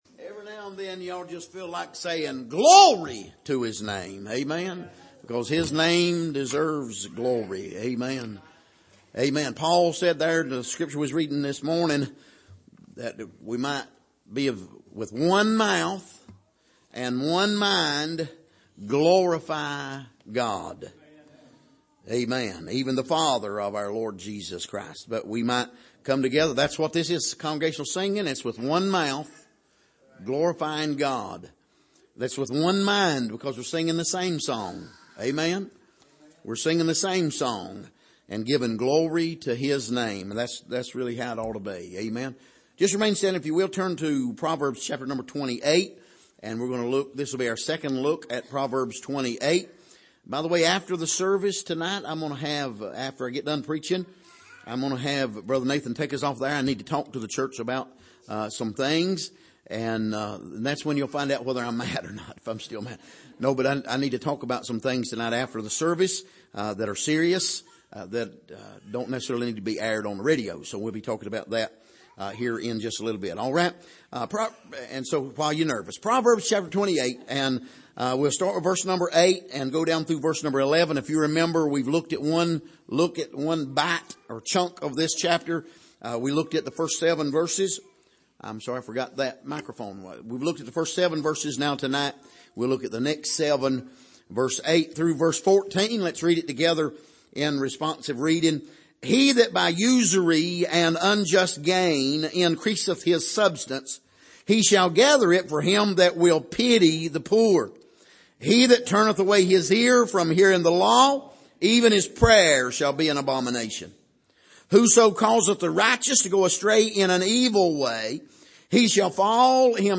Passage: Proverbs 28:8-14 Service: Sunday Evening